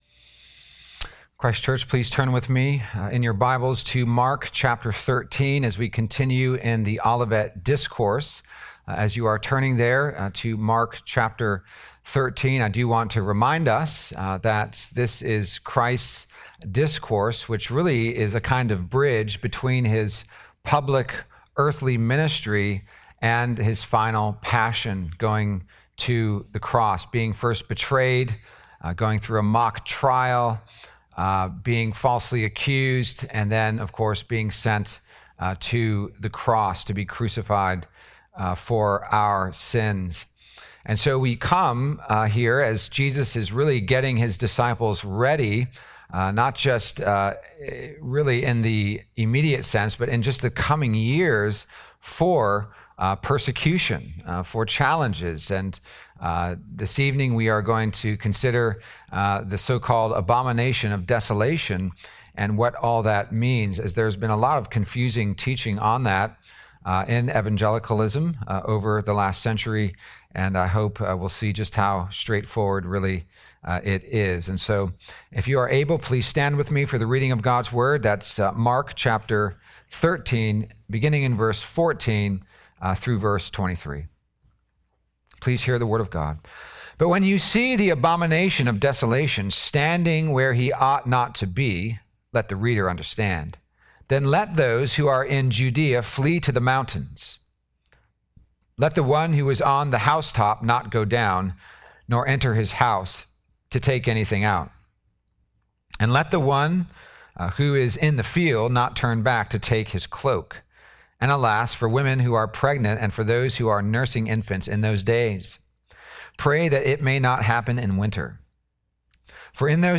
A Sermon To Remember: The Olivet Discourse, Part 3 Sermons podcast